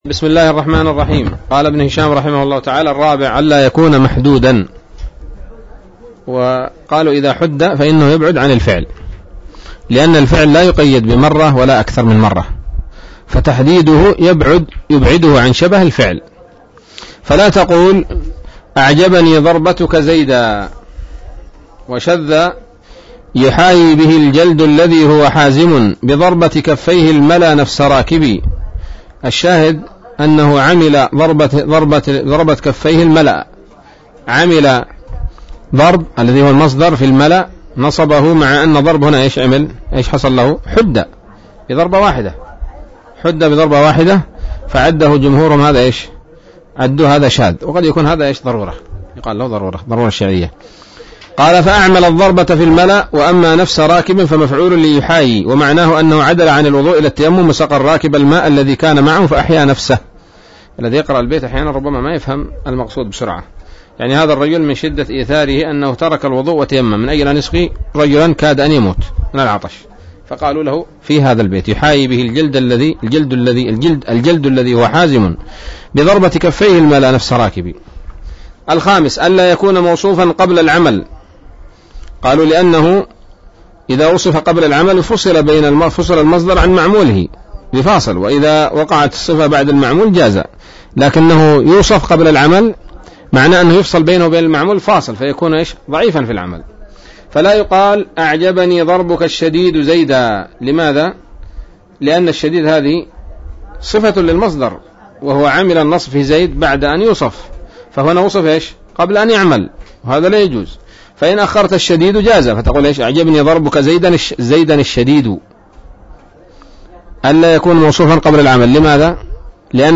الدرس السابع بعد المائة من شرح قطر الندى وبل الصدى